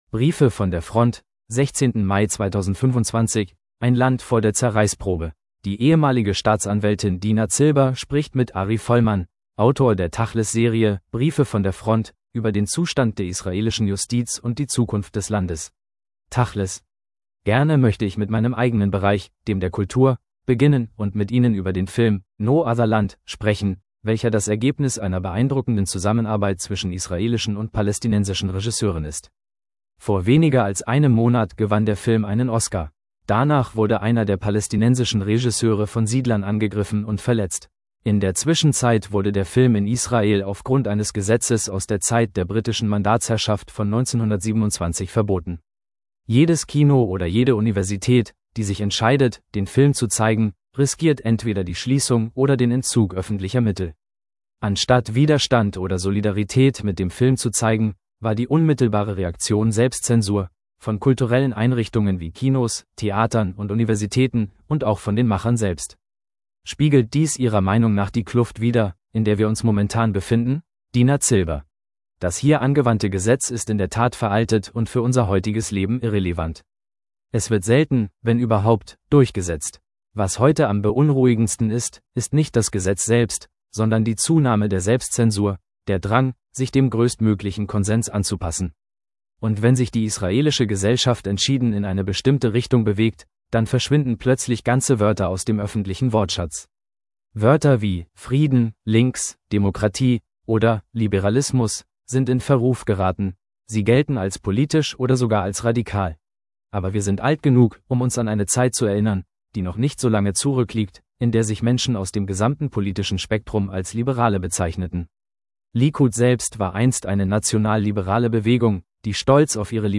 Die ehemalige Staatsanwältin Dina Zilber spricht mit Ari Folman, Autor der tachles-Serie «Briefe von der Front», über den Zustand der israelischen Justiz und die Zukunft des Landes.